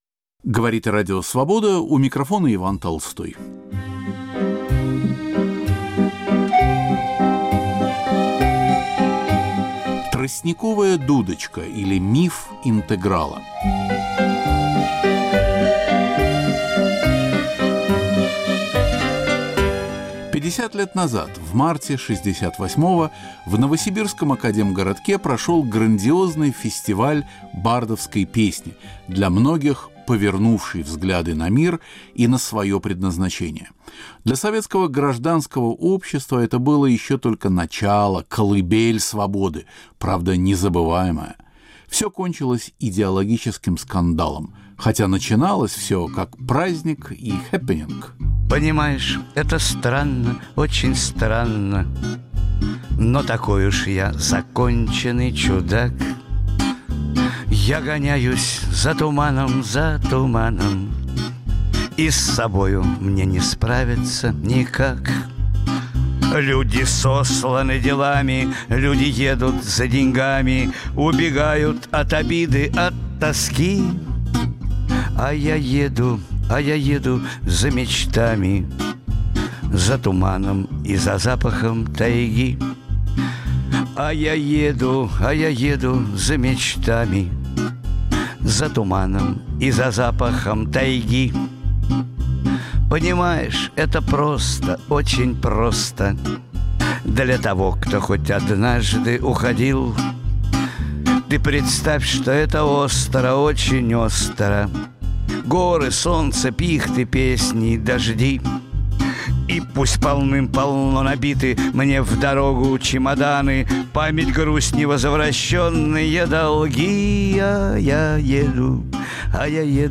Звучат редкие записи.